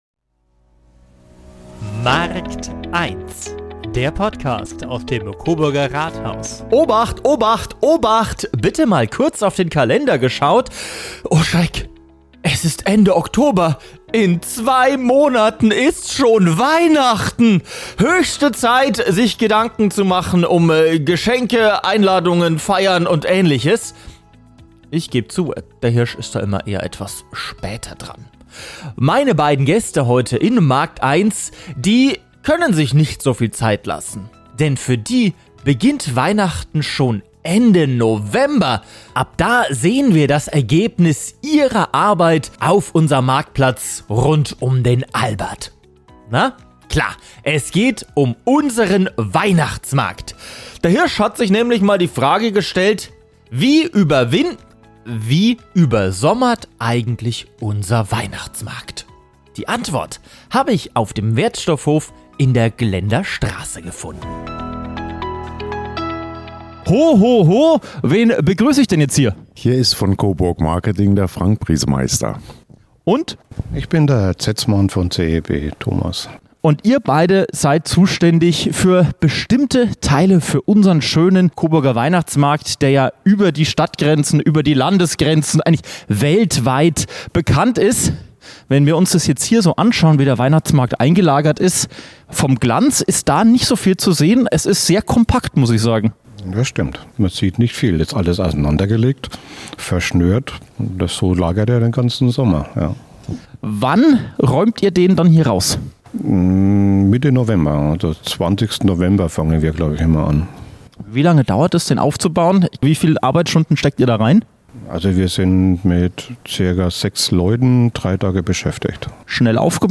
Alles andere als weihnachtlich ist es auf dem Wertstoffhof des Coburger Entsorgungsbetriebs (CEB). An Mulden werden kaputte Waschmaschinen und alte Möbel ausgeladen.